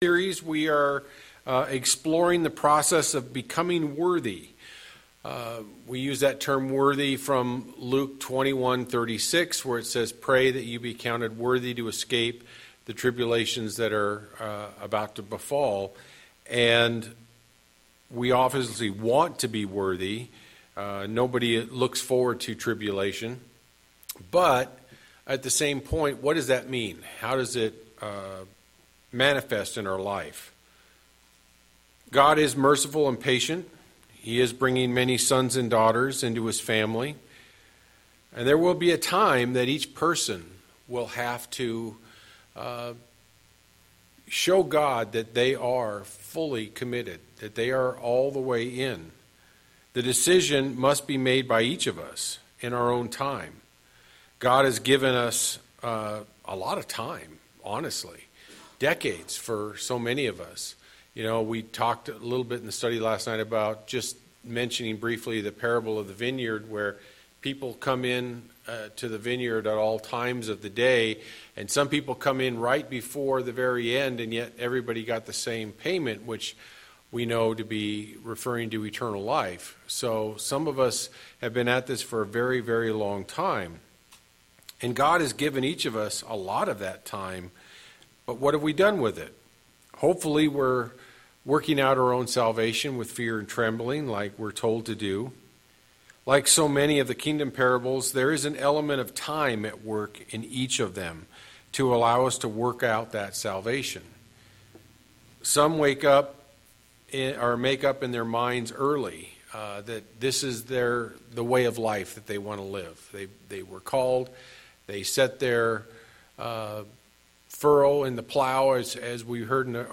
In this sermon series, we are exploring the process of becoming worthy to escape the tribulation that will someday come upon this world.